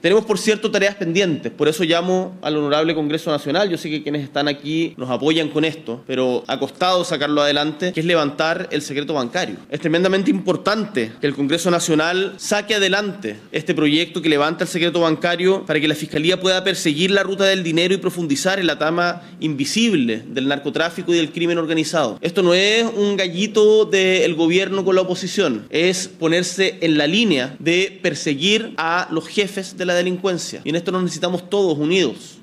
Durante la ceremonia realizada en La Moneda, el mandatario destacó que esta iniciativa forma parte de una política de Estado para fortalecer la persecución penal y modernizar las capacidades institucionales.